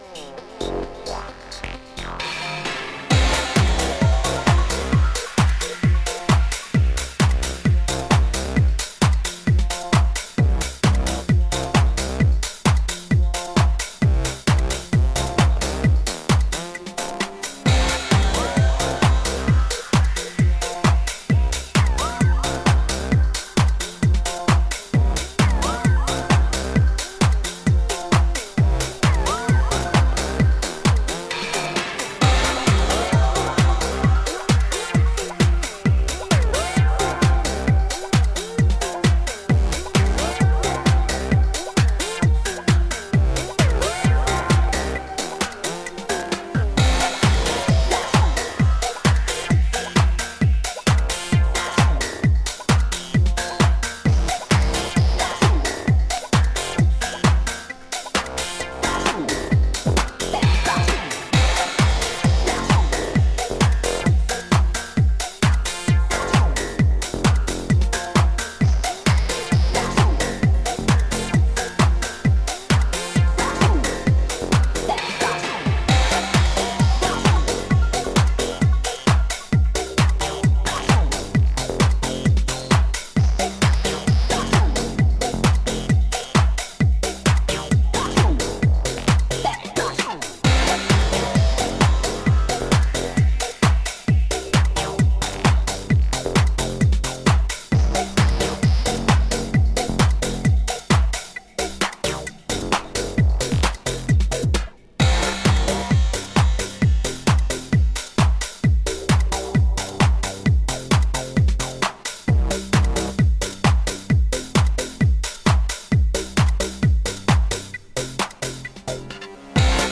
淡々とハイテンションな、南米勢にも通じるグルーヴ感覚です！